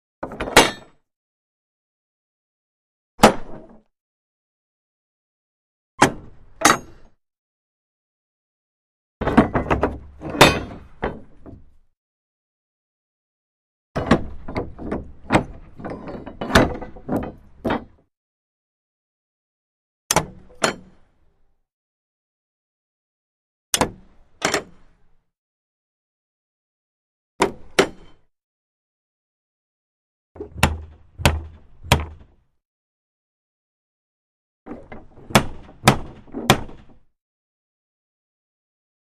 Large Wooden Main Gate 3; Bolts, Latches And Door Knocker, Exterior